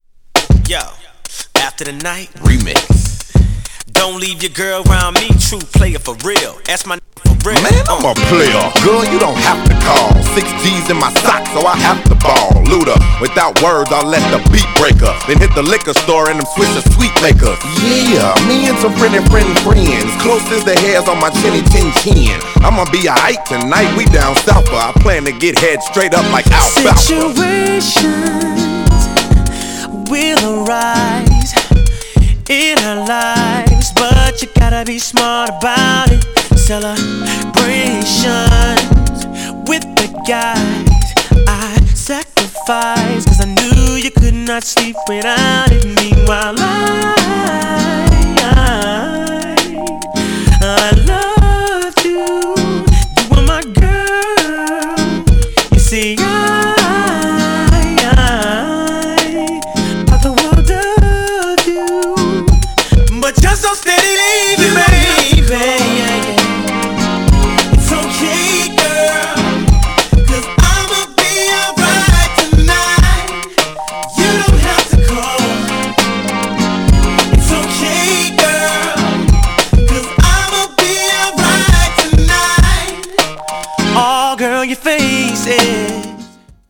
GENRE R&B
BPM 96〜100BPM